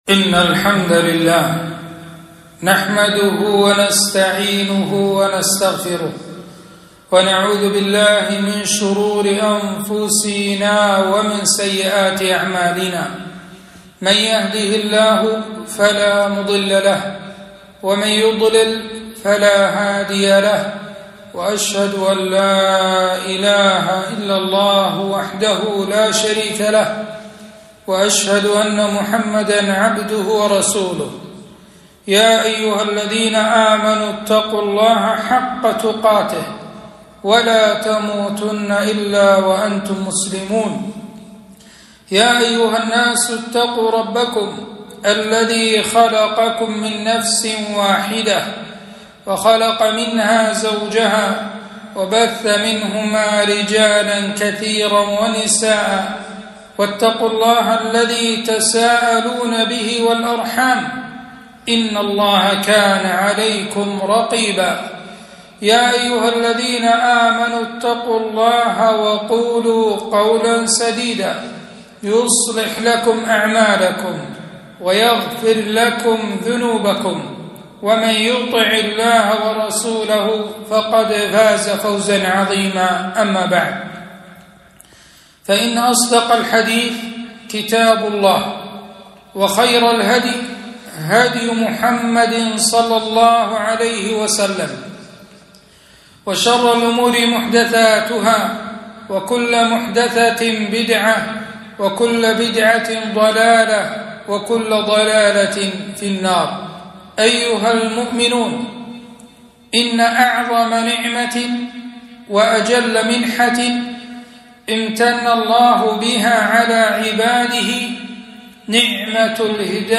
خطبة - ( وقالوا اتخذ الرحمن ولدا )